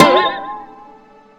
SFX弹簧1 000音效下载
SFX音效